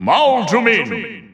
The announcer saying Villager's name in Korean releases of Super Smash Bros. 4 and Super Smash Bros. Ultimate.
Villager_Korean_Announcer_SSB4-SSBU.wav